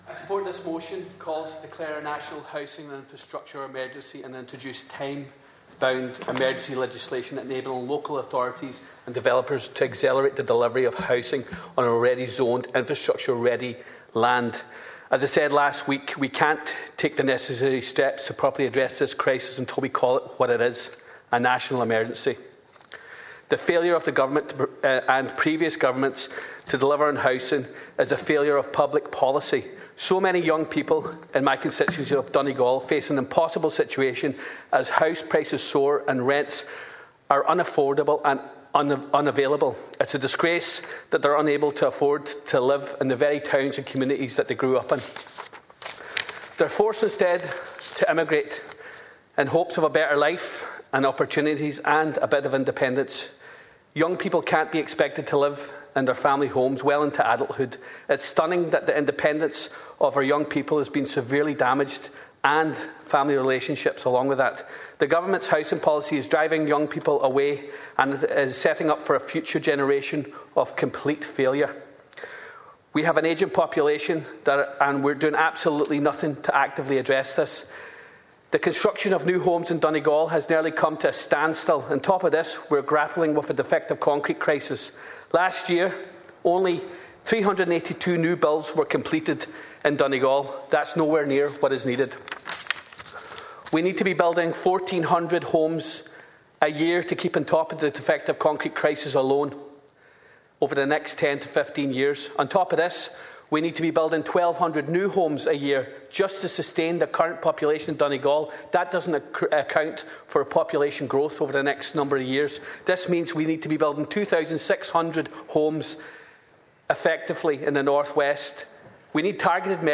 Deputy Charles Ward has told the Dáil that people are looking to move on with their lives and be rid of the burden that the defective concrete crisis has put on them and their families.
The Donegal Deputy was speaking on a Motion regarding Legislative and Structural Reforms to Accelerate Housing Delivery.